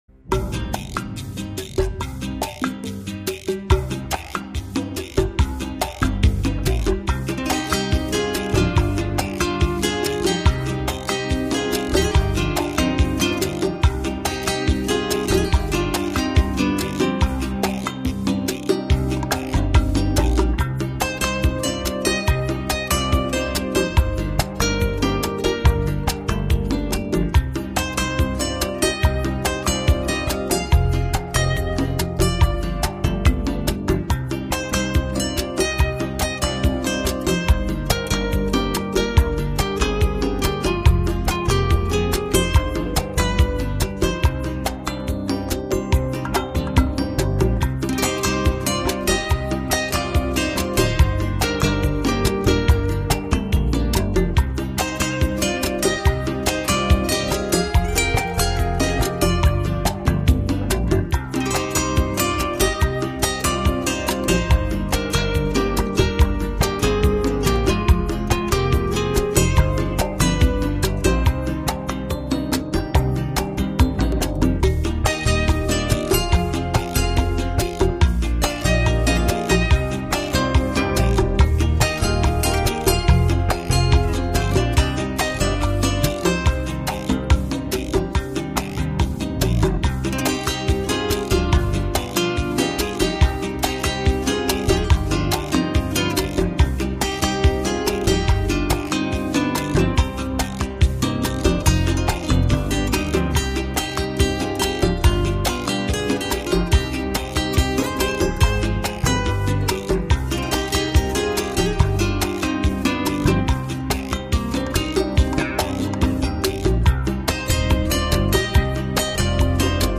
轻快自然的曲调和悠扬随和的风格是其特 点。
音质非常纯净透彻，充分体现了吉他音色饱满的颗粒感。